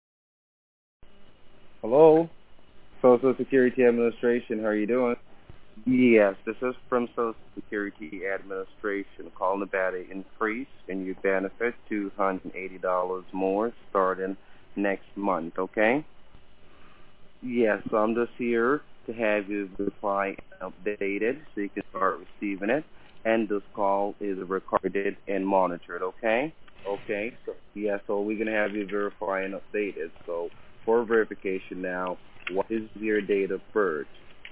Scams Robo Calls
This call was from late yesterday afternoon